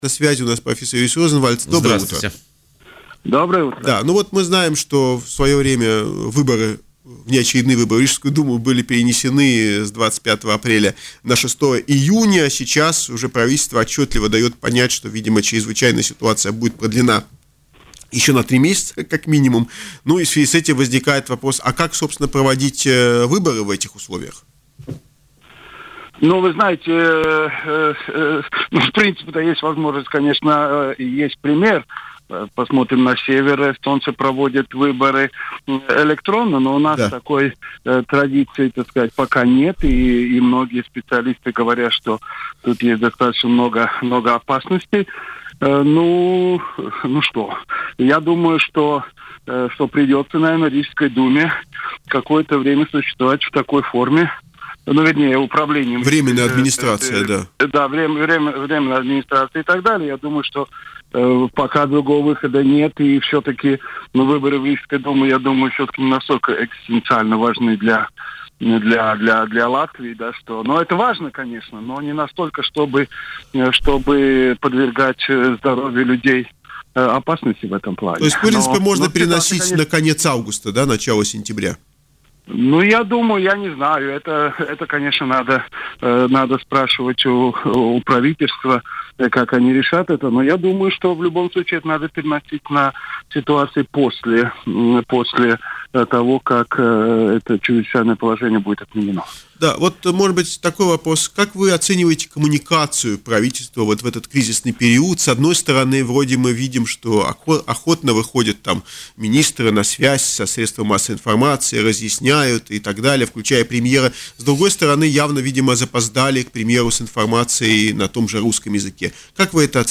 в эфире радио Baltkom